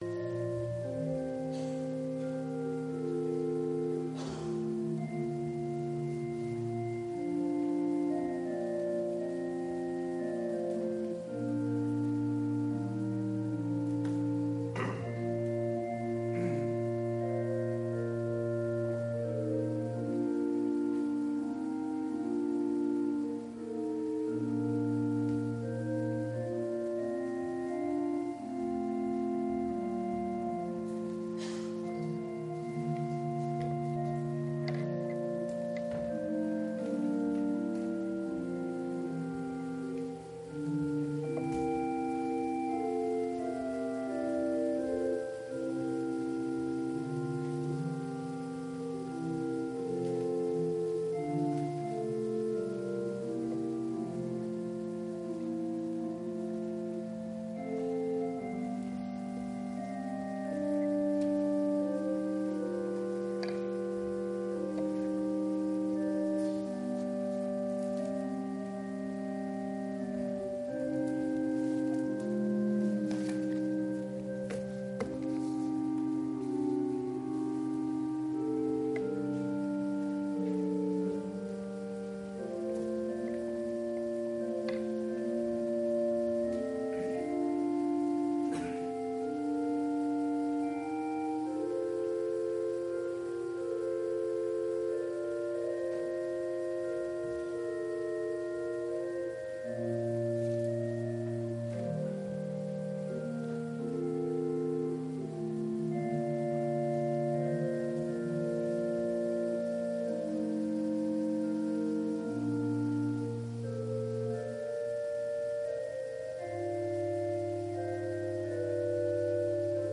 礼拝音源